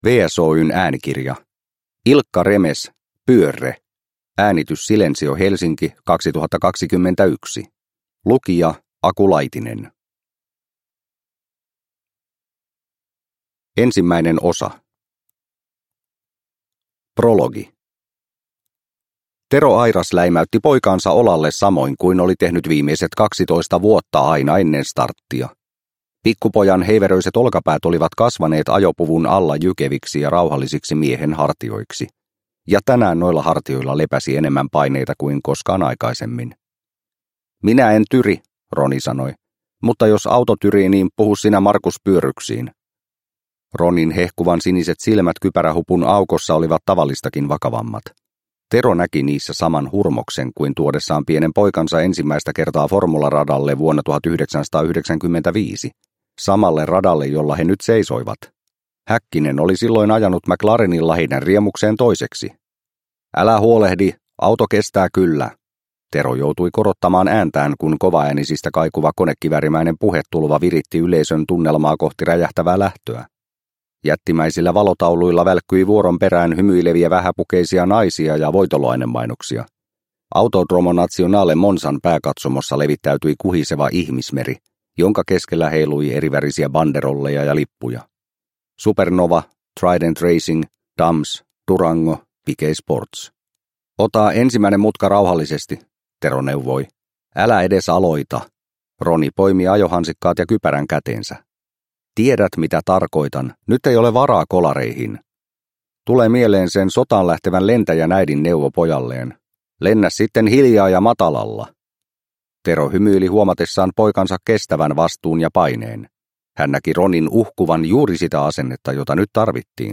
Pyörre – Ljudbok – Laddas ner